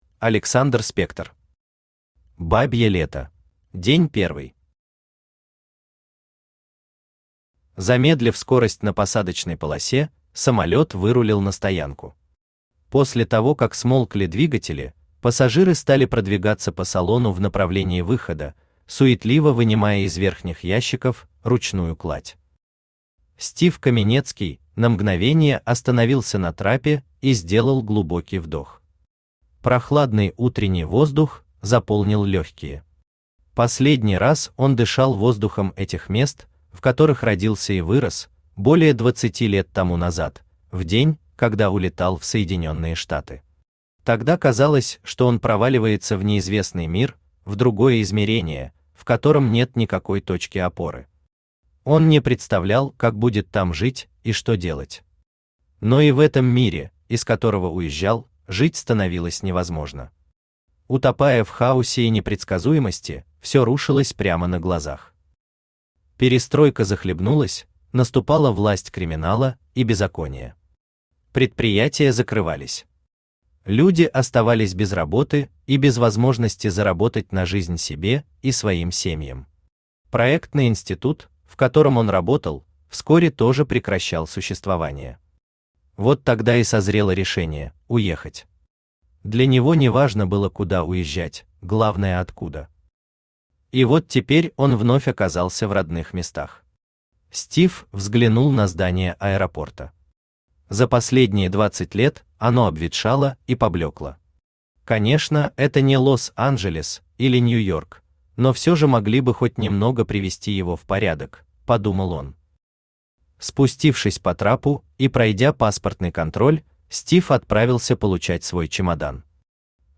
Aудиокнига Бабье лето Автор Александр Спектор Читает аудиокнигу Авточтец ЛитРес.